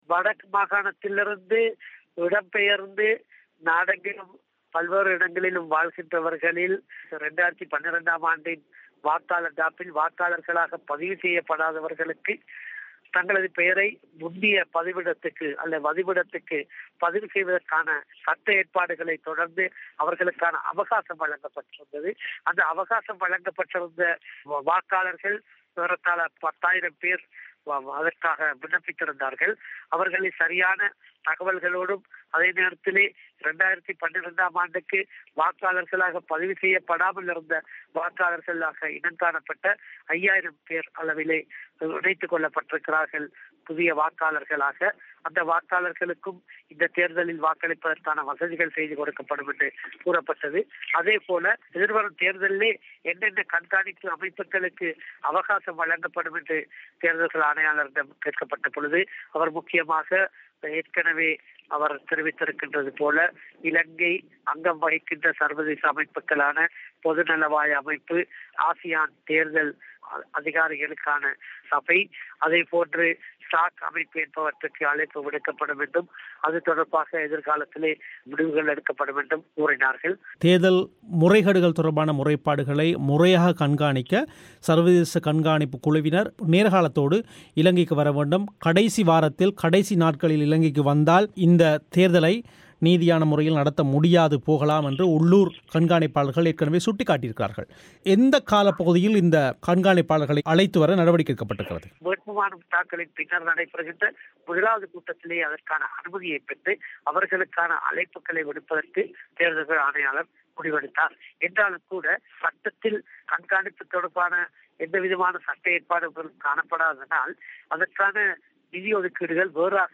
இலங்கை வடக்கு உள்ளிட்ட 3 மாகாணசபைளில் நடக்கவுள்ள தேர்தல்களுக்காக வெளிநாட்டுக் கண்காணிப்பாளர்களை அழைப்பது பற்றியும் வடக்கில் இராணுவத் தலையீட்டை தடுப்பது பற்றியும் கேட்கப்பட்ட கேள்விகளுக்கு துணை ஆணையாளர் எம்.எம். மொஹமட் பதில்